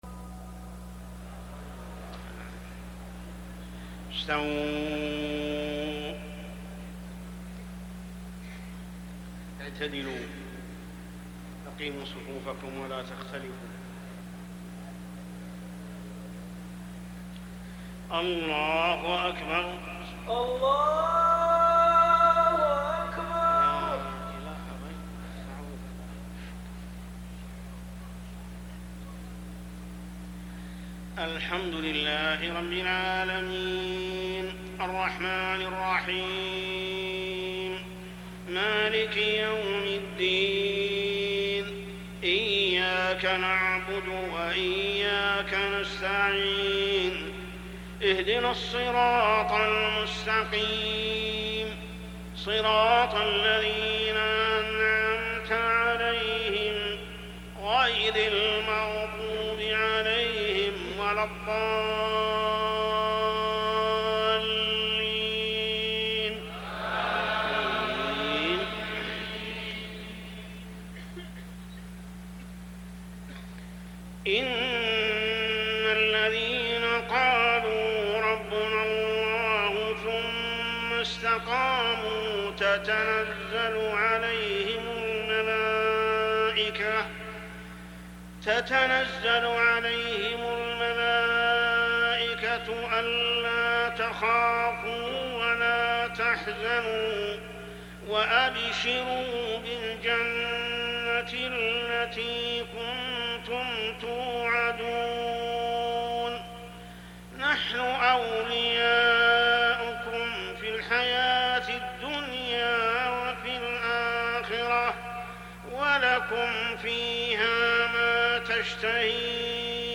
صلاة العشاء رمضان عام 1420هـ من سورة فصلت 30-36 > 1420 🕋 > الفروض - تلاوات الحرمين